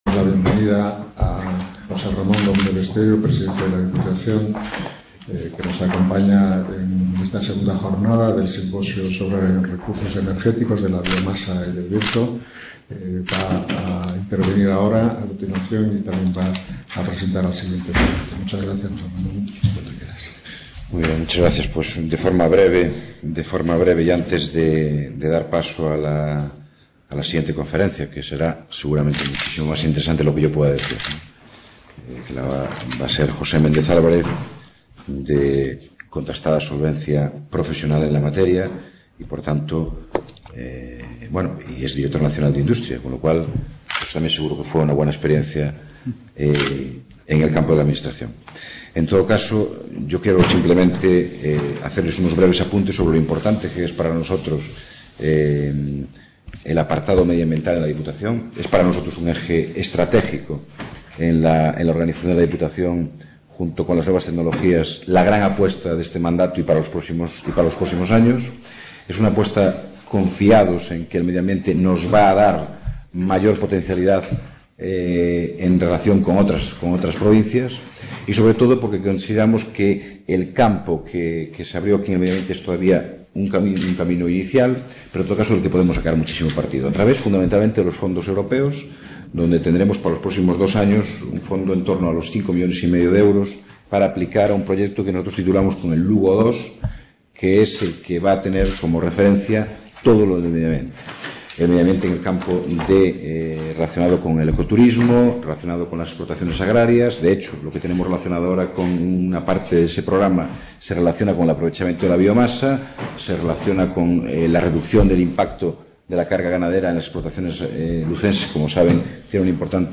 D. José Ramón Gómez Besteiro - Presidente de la Diputación Provincial de Lugo
Simposio sobre Recursos energéticos de la biomasa y del viento
| Tit: CONFERENCIAS | Autor:varios